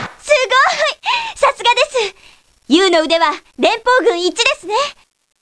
しかし、クリアできないときは得点によってモーリンのコメントが変わる。また、クリアしたときは被弾率によってコメントが変わる。